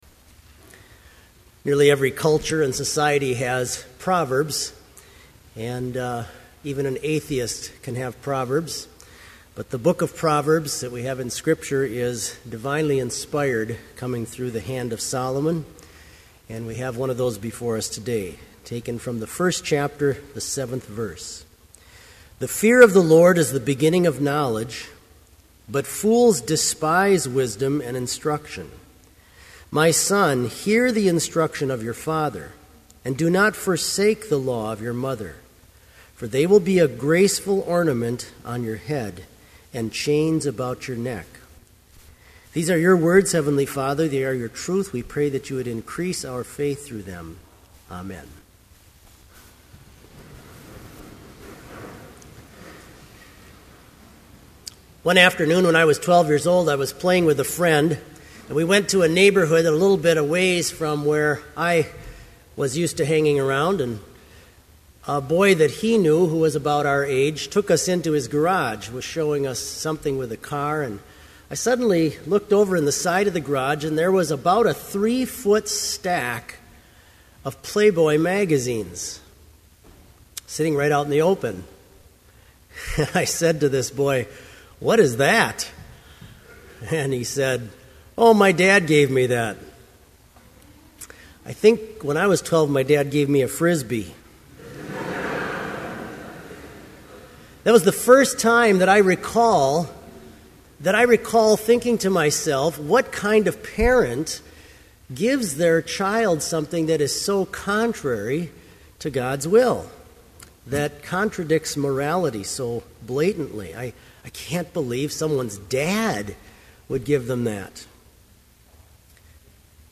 Sermon audio for Chapel - January 20, 2012